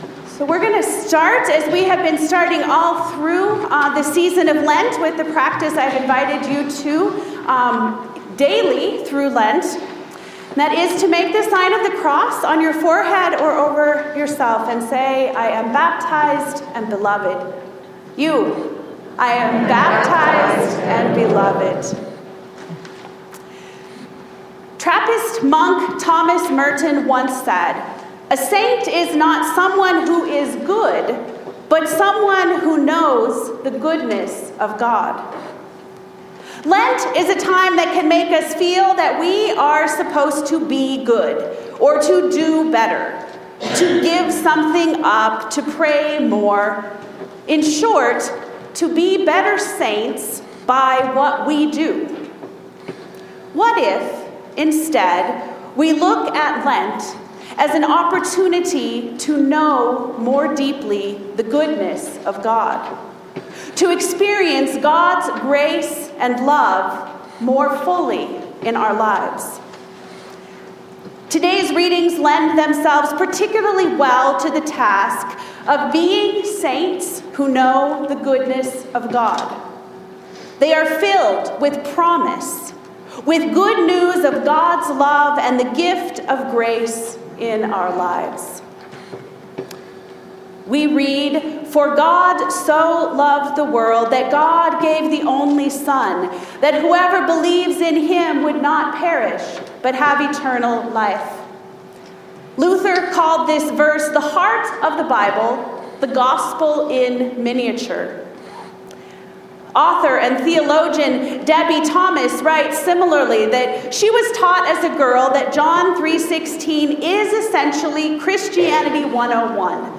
Sermon test - All Saints Lutheran Church, ELCA